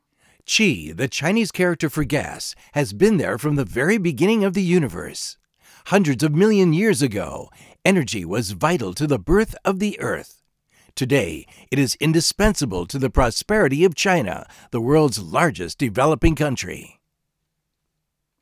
以下为两条录音，第一条为语音模式状态下的录音片段，第二条为直通状态下的录音片段。
英文广告-直通模式
从第二条可以听出，MV51的频响较宽阔。
english_ads_direct.wav